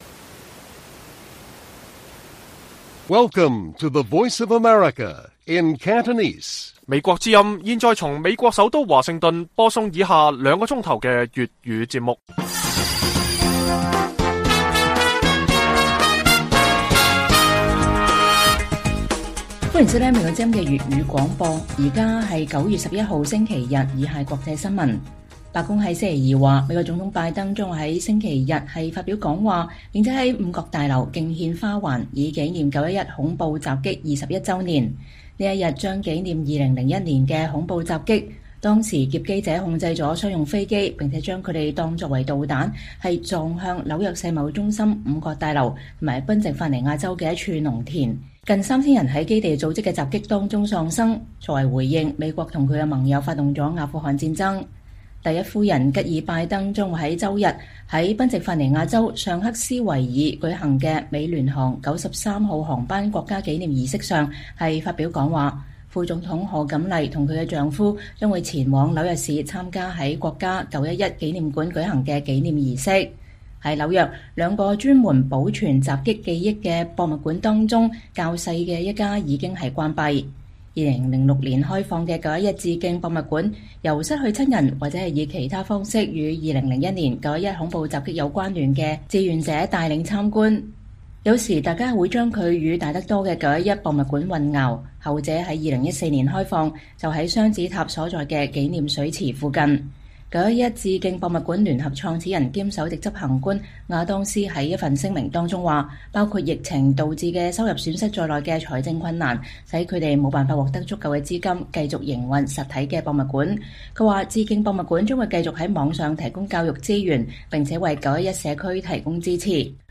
粵語新聞 晚上9-10點: 拜登夫婦將參加五角大樓、賓夕法尼亞州的911事件紀念活動